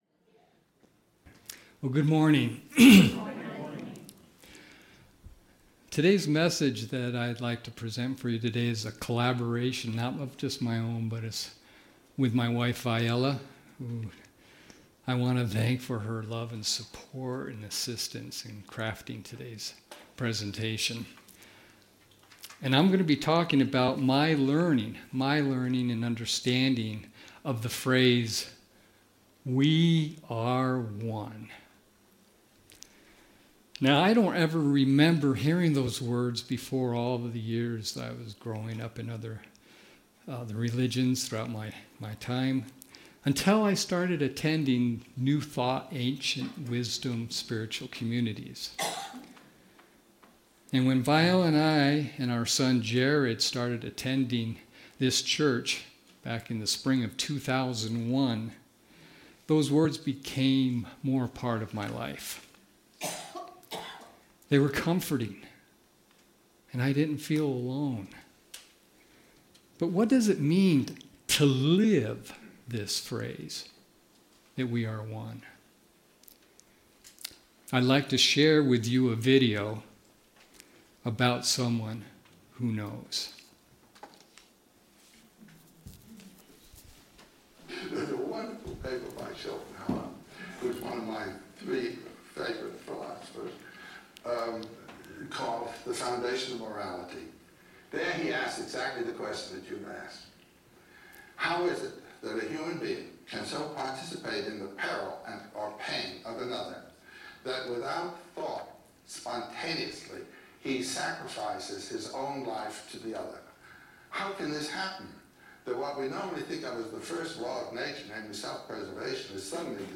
The audio recording (below the video clip) is an abbreviation of the service. It includes the Lesson, Meditation, and Featured Song.